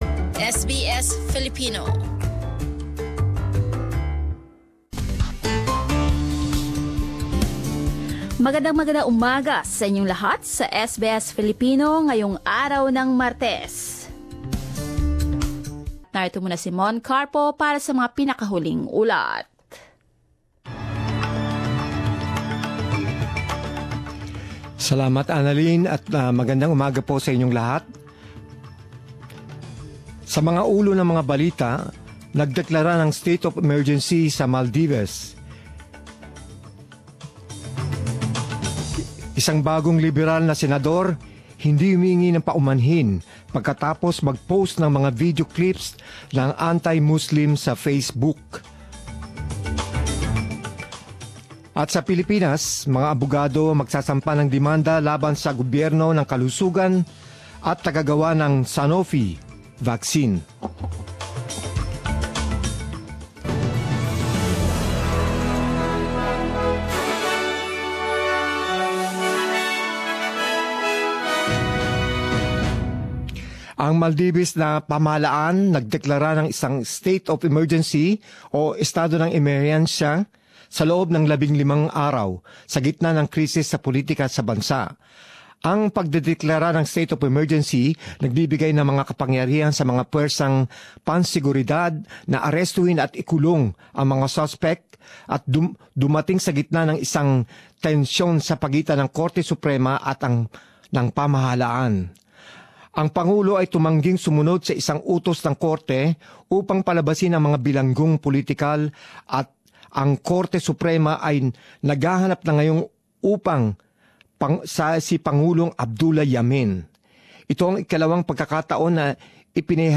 Mga balita sa alas diyes ng umaga sa wikang Filipino